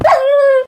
wolf_death.ogg